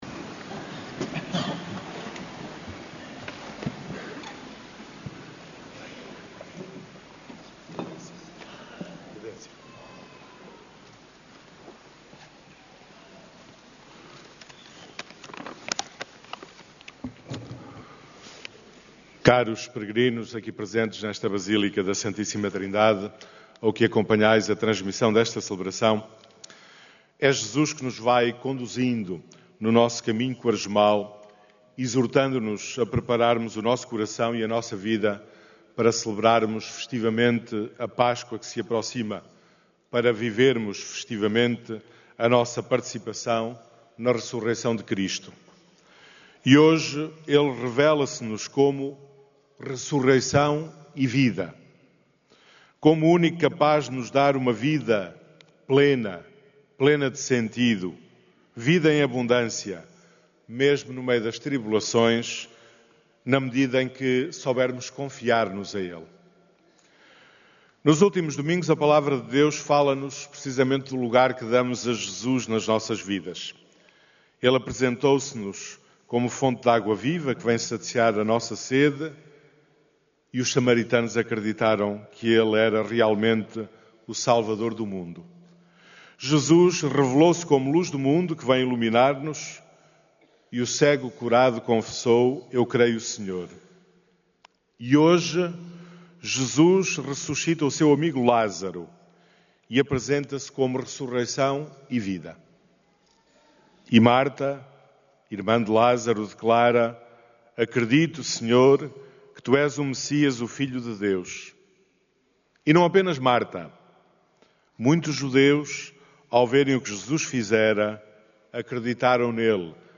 Áudio da homilia